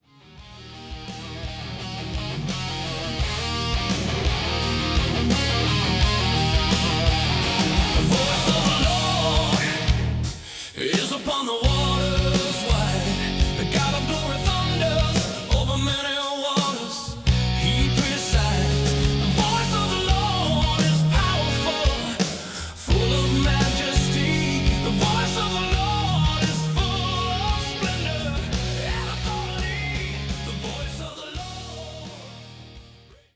Thunderous power meets reverent worship
Heavy electric guitar riffs and soaring solos
Driving bass lines that anchor each song
Distinctive rock drumming with dynamic fills
Raw, authentic Southern rock vocals